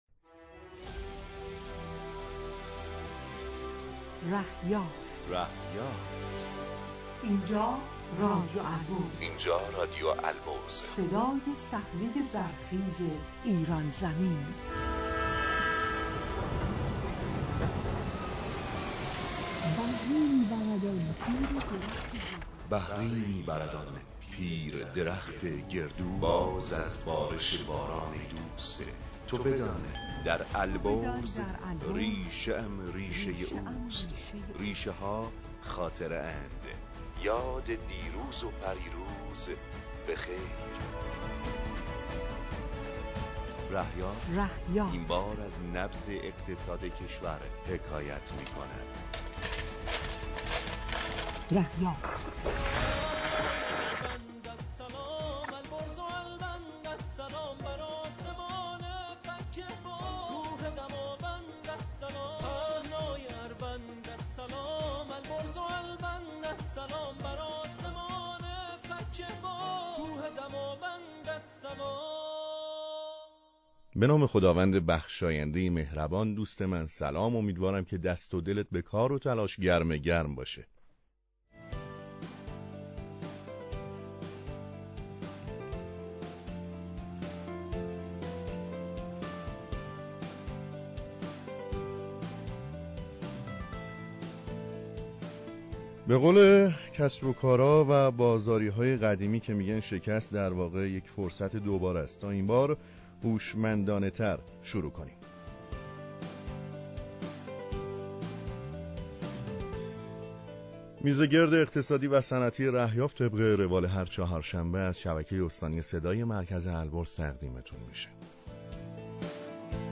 معرفی مرکز آنالیز شیمیایی جهاد دانشگاهی در برنامه زنده "رهیافت" از صدا و سیمای مرکز البرز